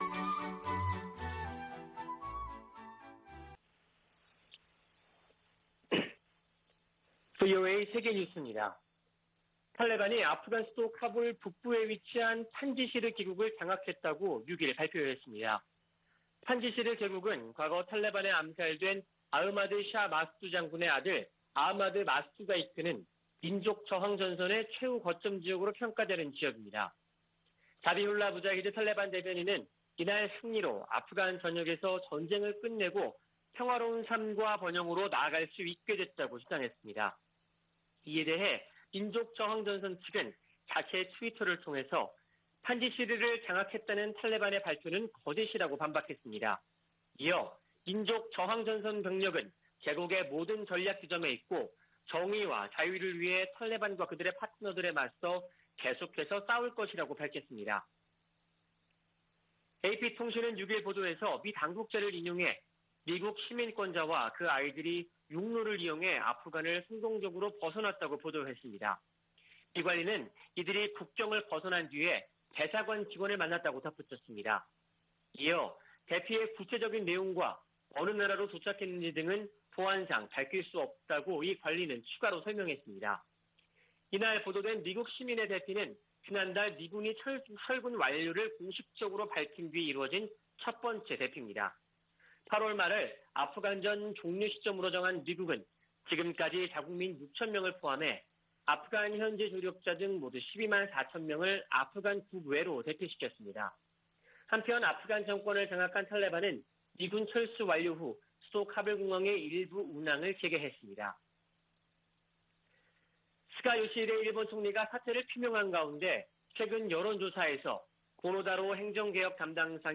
VOA 한국어 아침 뉴스 프로그램 '워싱턴 뉴스 광장' 2021년 9월 7일 방송입니다. 미 공화당 의원들은 북한 문제와 관련해 강력한 압박을 촉구하고 있는 가운데 일부 민주당 의원은 강경책은 해법이 아니라고 밝혔습니다. 북한이 대륙간탄도미사일 발사를 선택하더라도 이에 맞서 임무 수행할 준비가 돼 있다고 미 북부사령관이 밝혔습니다. 북한의 사이버 위협이 진화하고 있지만 미국의 대응은 제한적이라고 워싱턴의 민간단체가 지적했습니다.